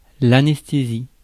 Prononciation
Variantes orthographiques (obsolète) anæsthésie Synonymes inconscience Prononciation France: IPA: [a.nɛs.te.zi] Le mot recherché trouvé avec ces langues de source: français Traduction 1.